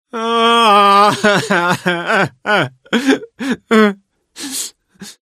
Play, download and share male sylvari cry original sound button!!!!